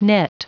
Prononciation du mot net en anglais (fichier audio)
Prononciation du mot : net